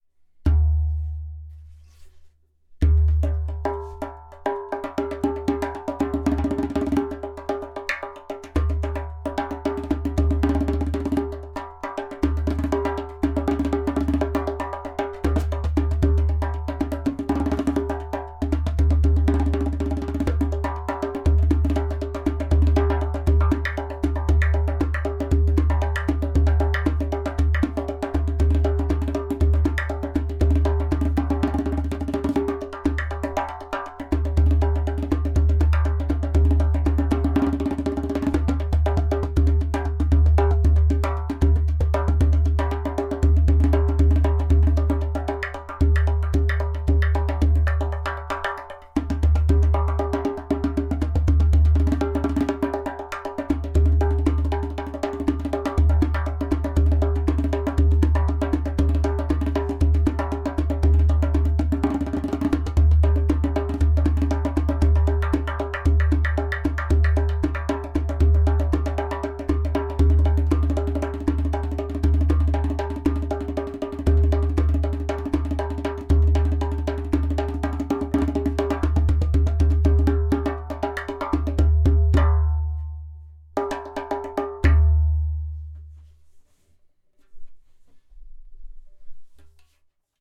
Premium Matrix True Bass 11 inch Dohola with Goat skin
In this line of darbukas materials like clay, glaze and natural skin met in a magical way which brings into life a balanced harmonic sound.
• Strong and super easy to produce clay kik (click) sound
• Deep bass
• Even tonality around edges.
• Beautiful harmonic overtones.